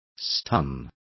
Also find out how pasmaba is pronounced correctly.